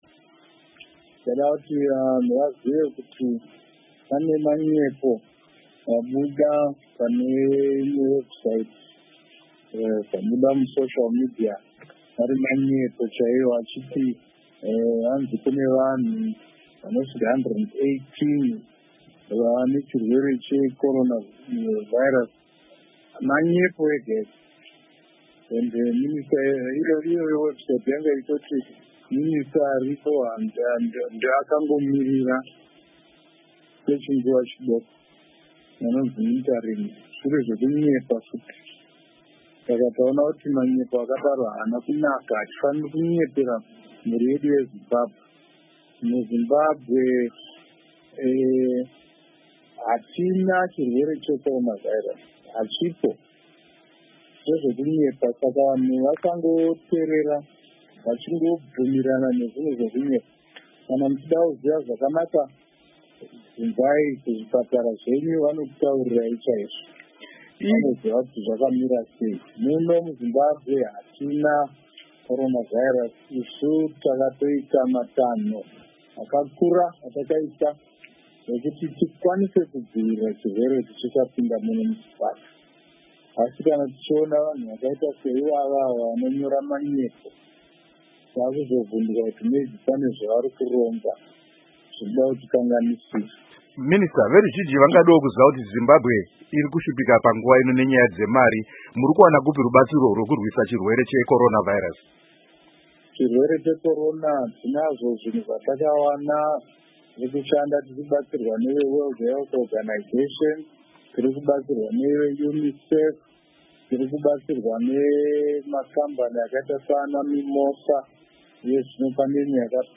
Izvi zvarambwa negurukota rezvehutano, Dr. Obadiah Moyo, muhurukuro yavaita neStudio7.
Hurukuro naDoctor Obadiah Moyo